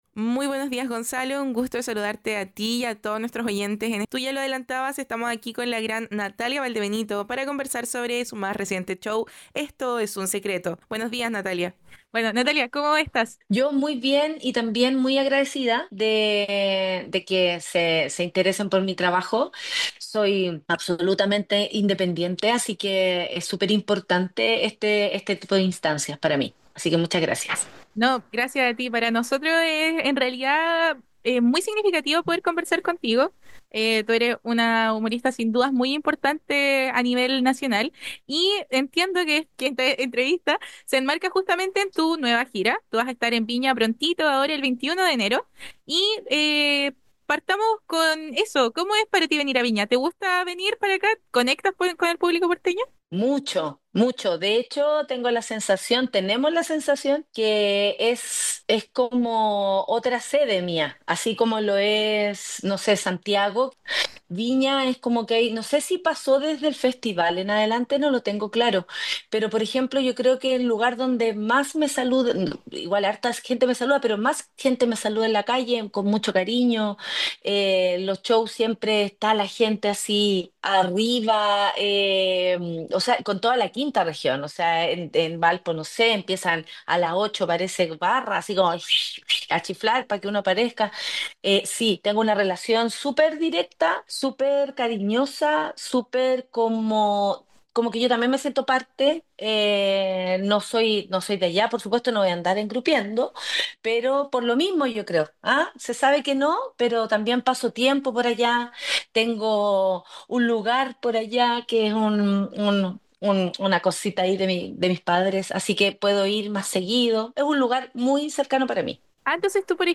Este martes 21 de enero llegará hasta Viña del Mar, y en UCV Radio pudimos conversar en exclusiva con ella. Sus procesos creativos, sus reflexiones sobre su trayectoria, sus anécdotas familiares y su profundo amor por el teatro en esta conversación extendida En el escenario de la comedia chilena, Natalia Valdebenito brilla con una autenticidad que desafía los moldes.